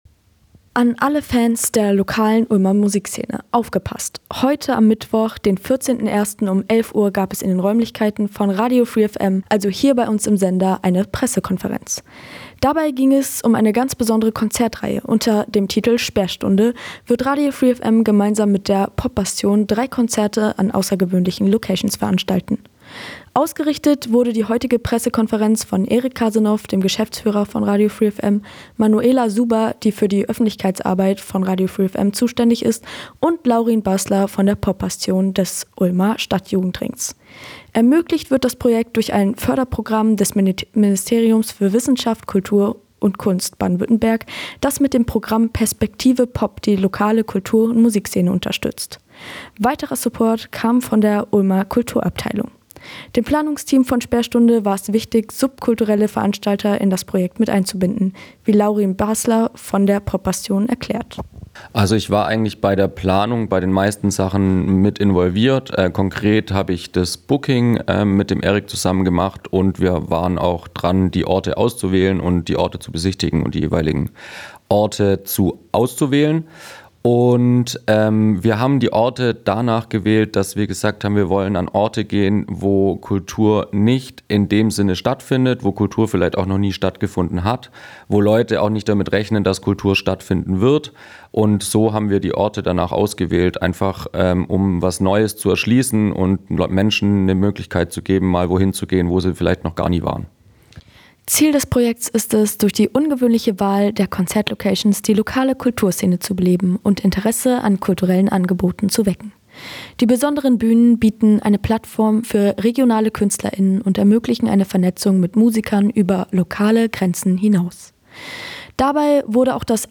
Pressekonferenz zum Projekt Sperrstunde
Pressekonferenz Sperrstunde_0.mp3